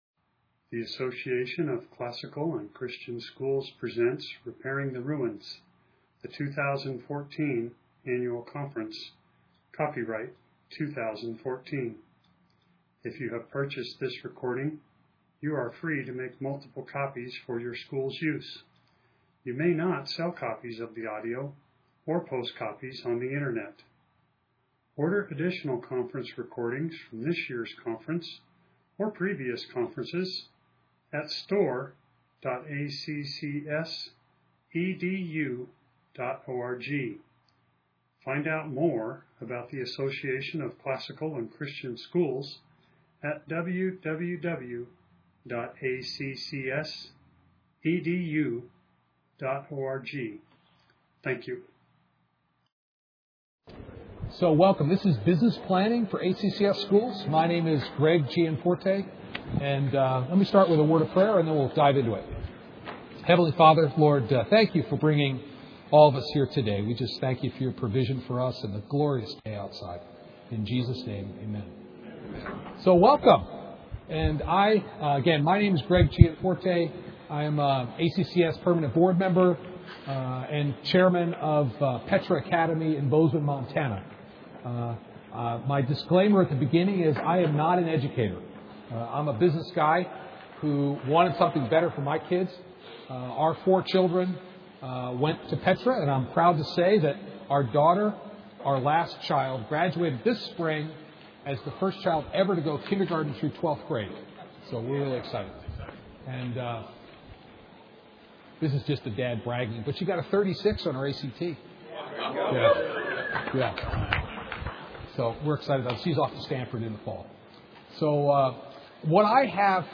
2014 Leaders Day Talk | 0:53:25 | Leadership & Strategic, Marketing & Growth
This session will introduce various business planning tools useful for administrators and board members of ACCS schools. The presenter will draw on his business experience and over 10 years as chairman of Petra Academy in Bozeman, Montana–expect an interactive session.